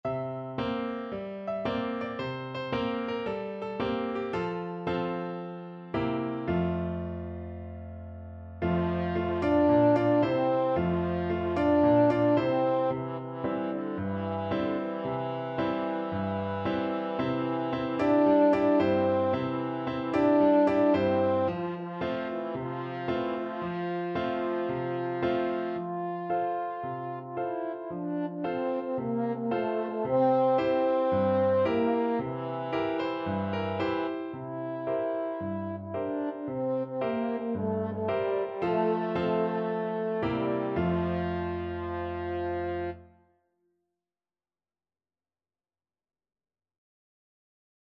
French Horn version
4/4 (View more 4/4 Music)
Cheerfully! =c.112
Traditional (View more Traditional French Horn Music)